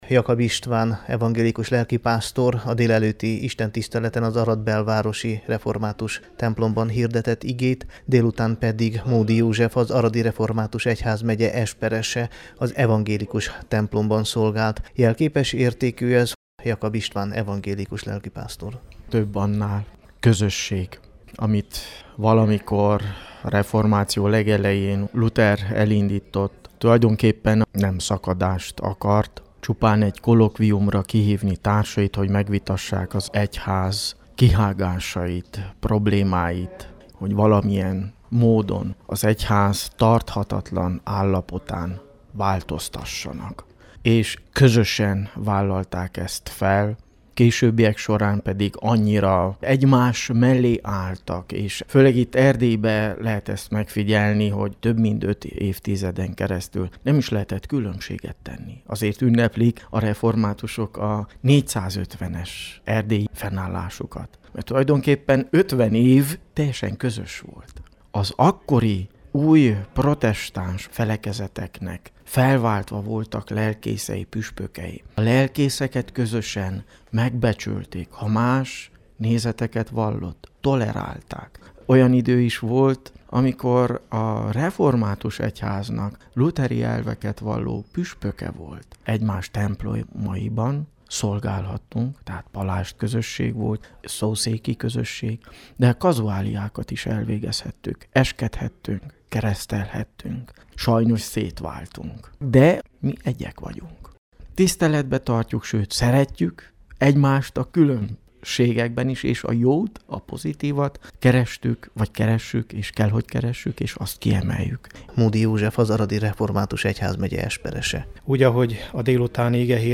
Aradon közösen ünnepelték meg a reformáció 500. évfordulóját a református és az evangélikus-lutheránus gyülekezetek.
reformacio_500_aradon.mp3